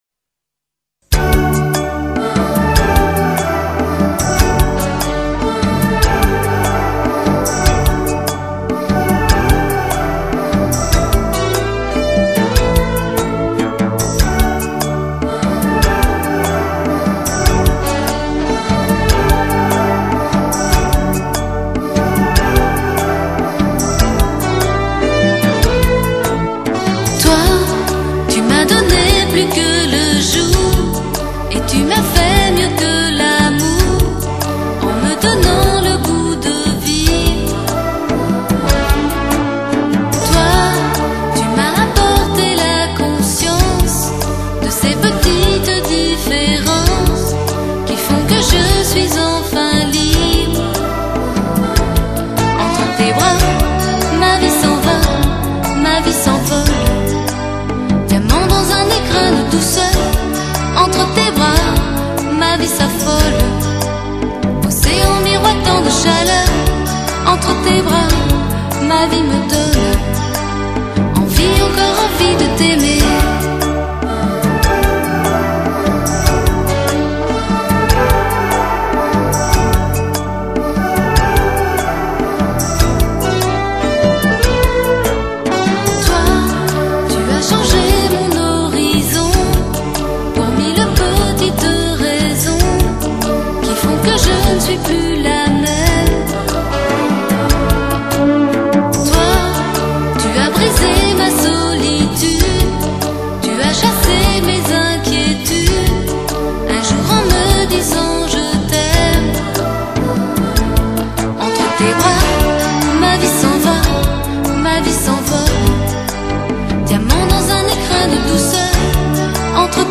감미로운 샹송